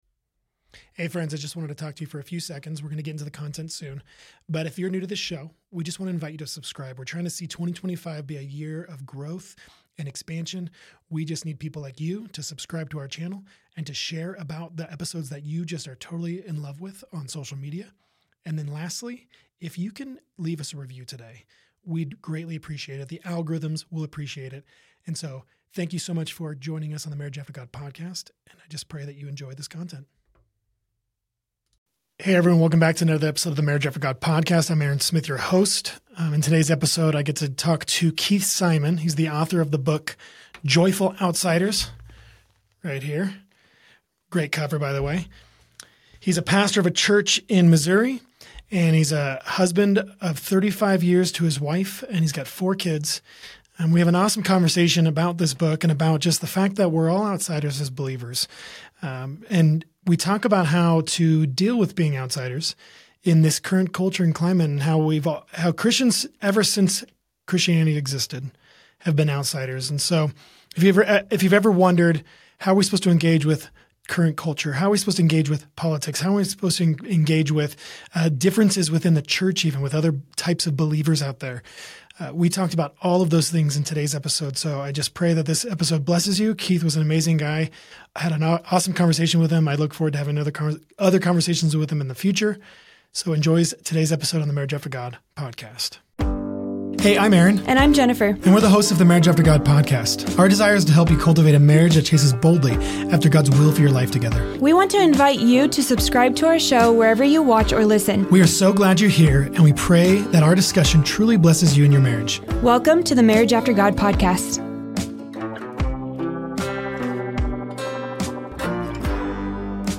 In this thought-provoking conversation, we explore: